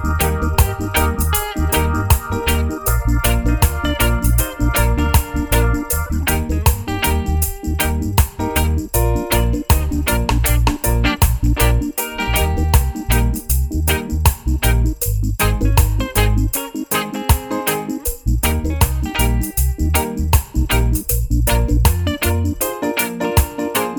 no Backing Vocals Reggae 4:06 Buy £1.50